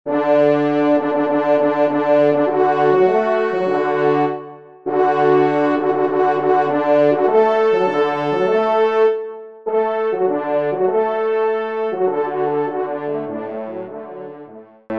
4ème Trompe